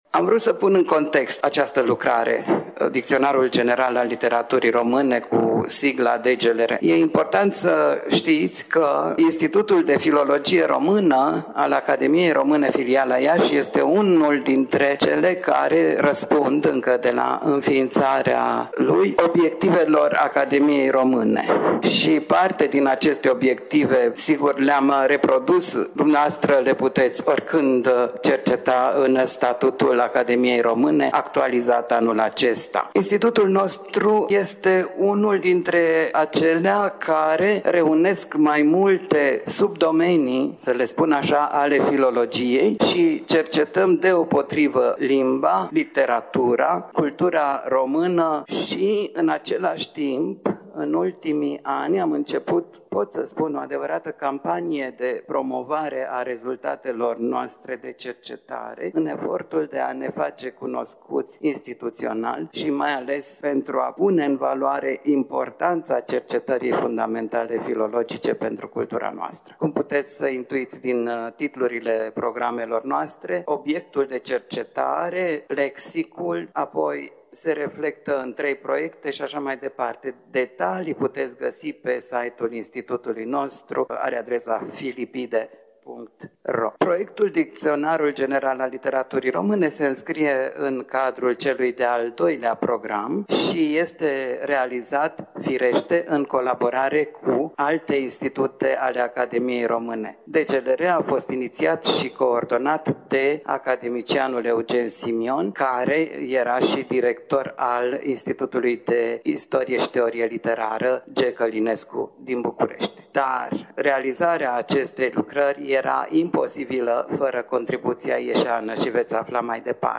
La Iași, în perioada 4–5 septembrie 2025, s-a desfășurat Conferința anuală a Muzeului Municipal „Regina Maria”, manifestare aflată la ediția a VI-a. Conferința s-a intitulat Din istoria orașului Iași: Oameni, Locuri, Instituții.